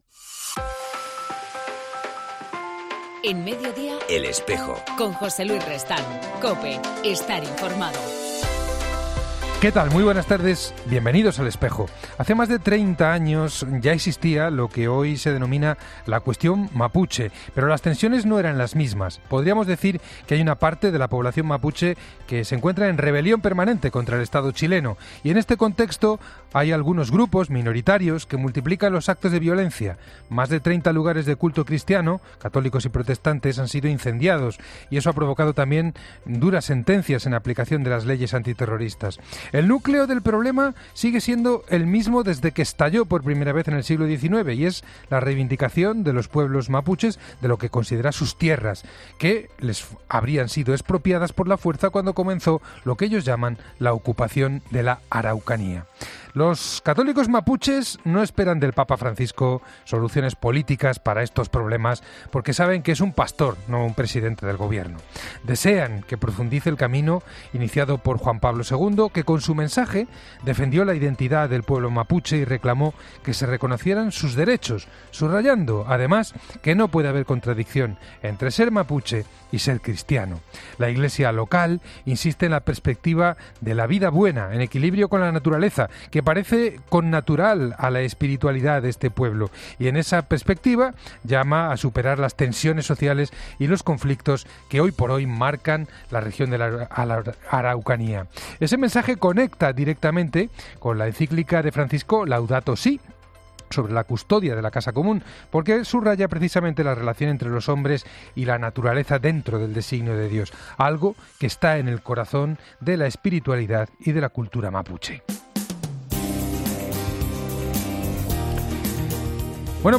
En El Espejo dle 17 de enero entrevistamos a David Martínez de Aguirre, Obispo de Puerto Maldonado